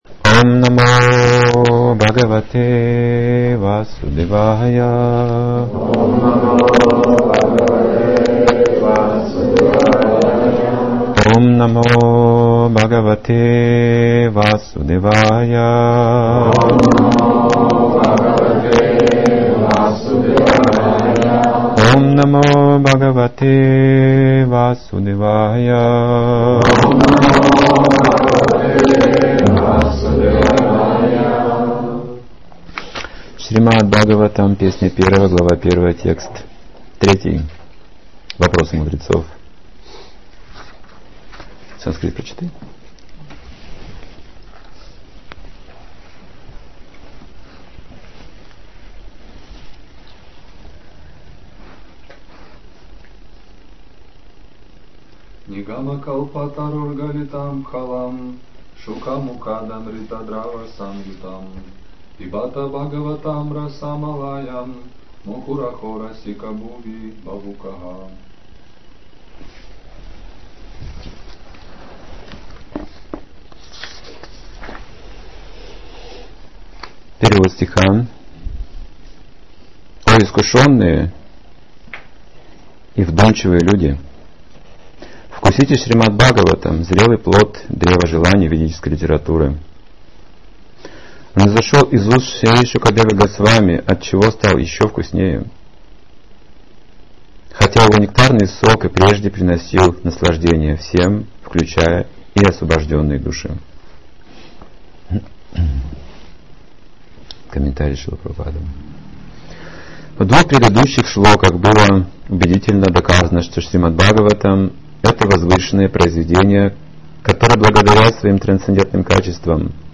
Лекция 1 - Очистить сердце